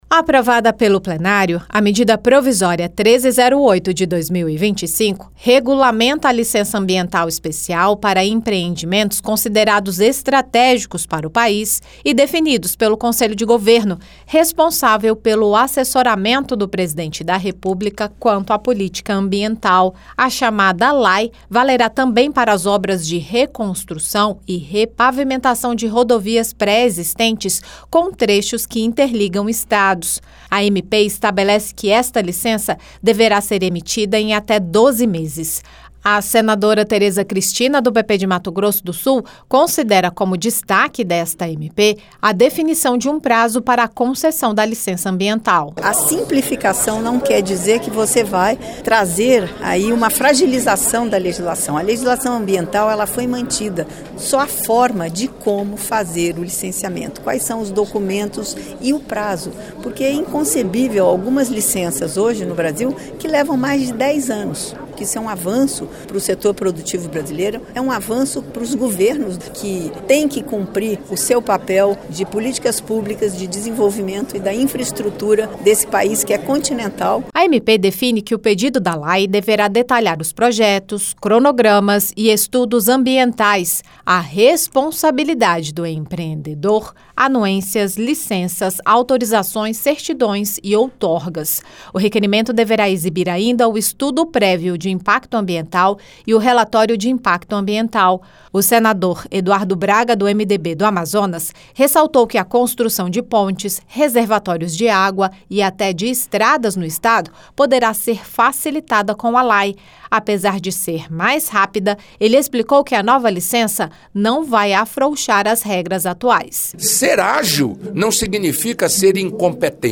O Plenário aprovou a Medida Provisória 1308/2025, que cria a licença ambiental especial (LAE) para empreendimentos considerados estratégicos pelos governos federal e estaduais. A senadora Tereza Cristina (PP-MS) destacou o prazo de 12 meses para a emissão da LAE respeitadas as exigências. Já o senador Eduardo Braga (MDB-AM) negou que a LAE afrouxe as regras atuais para a concessão das licenças ambientais.